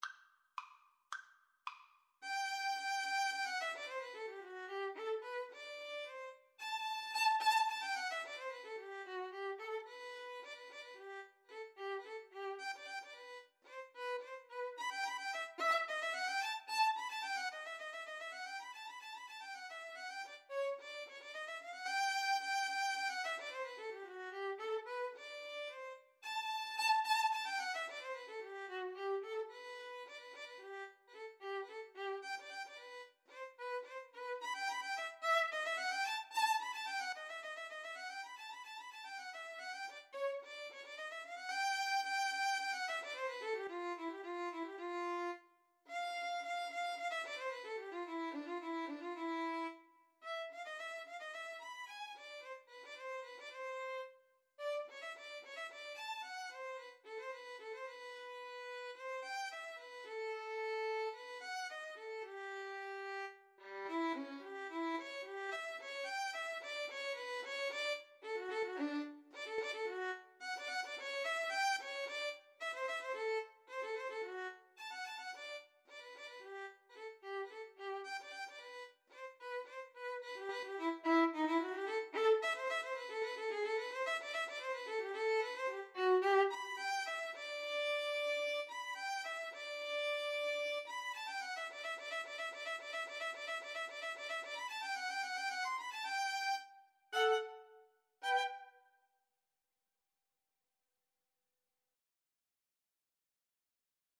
2/4 (View more 2/4 Music)
Allegro assai = 110 (View more music marked Allegro)
Classical (View more Classical Violin Duet Music)